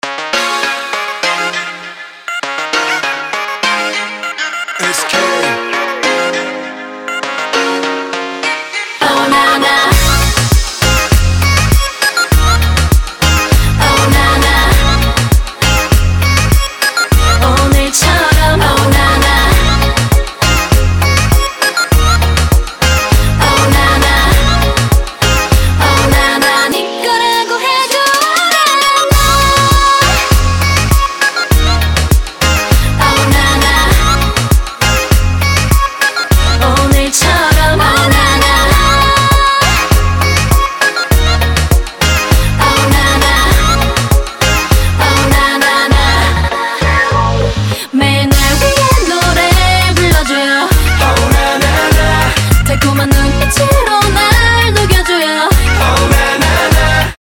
• Качество: 256, Stereo
поп
dance
приятные
танцевальные
K-Pop
korean pop